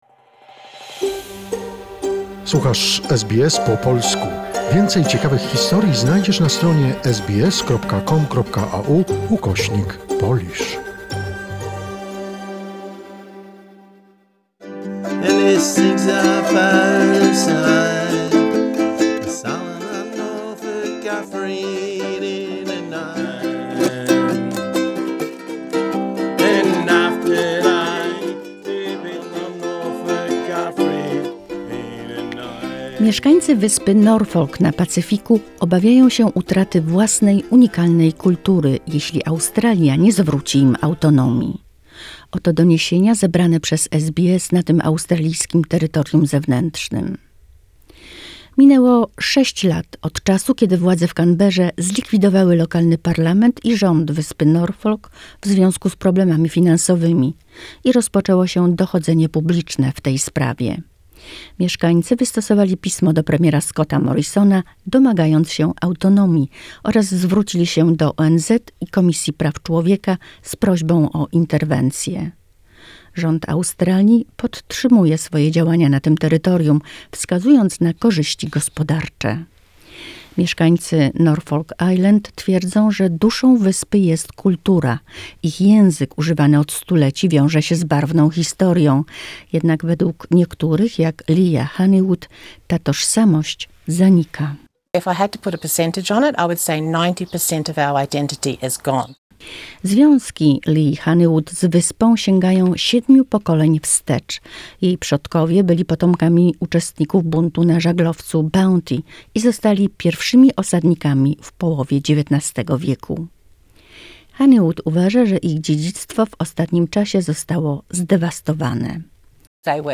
Residents of Norfolk [[nor-fik]] Island are warning they may lose their unique culture if self-rule isn’t returned to them by Australia.